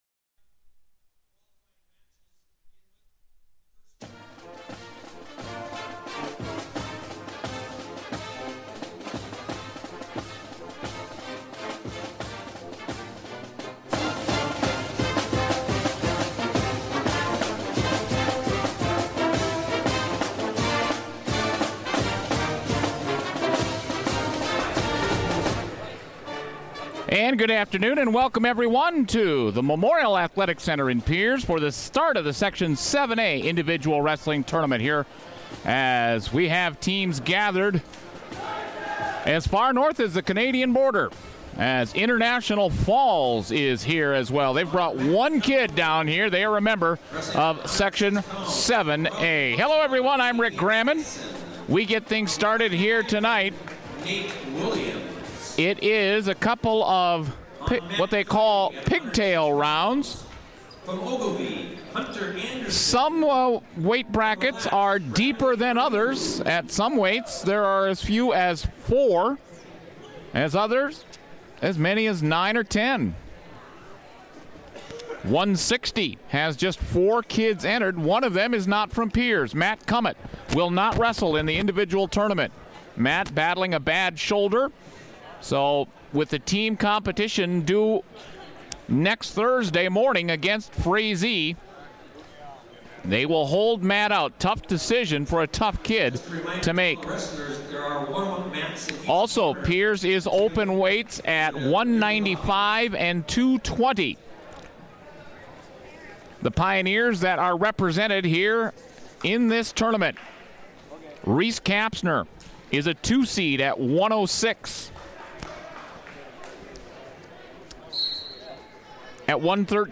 It's Day 1 of the Section 7A Individual Wrestling Tournament in Pierz.